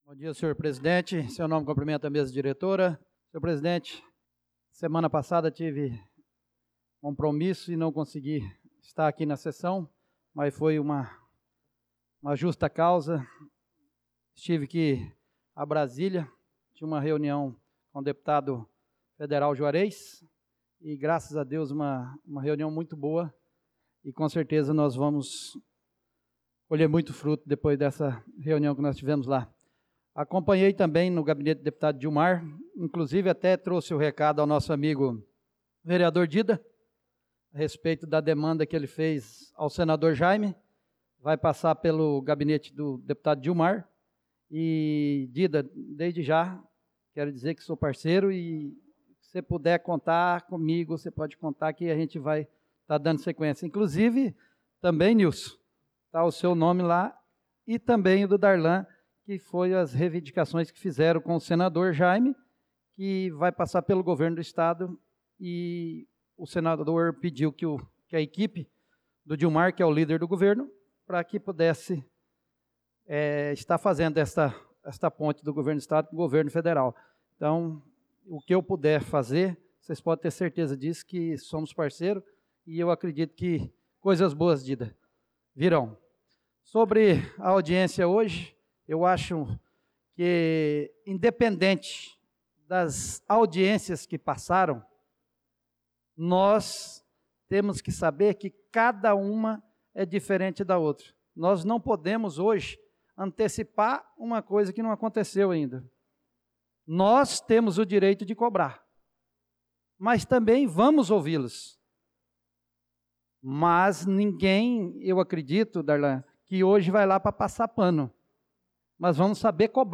Pronunciamento do vereador Marcos Menin na Sessão Ordinária do dia 18/03/2025